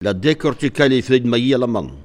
Elle provient de Saint-Jean-de-Monts.
Locution ( parler, expression, langue,... )